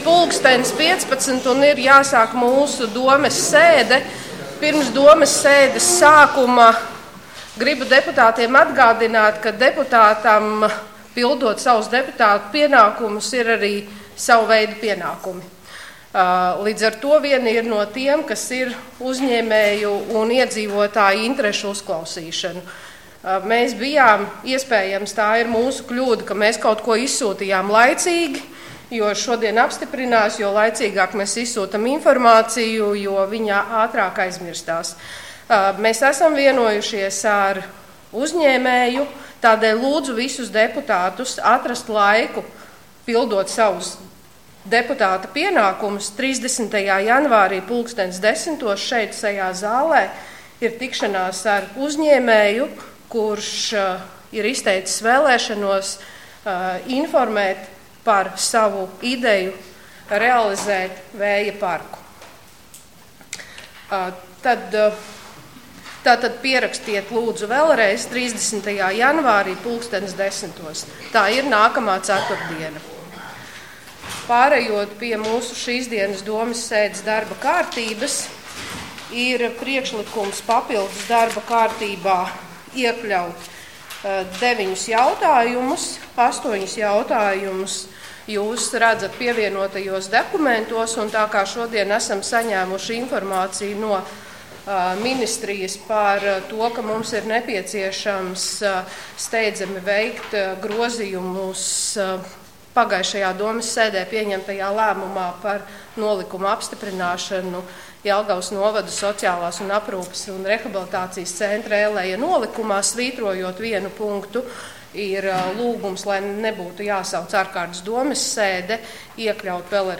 Domes sēde Nr. 2